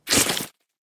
blood1.ogg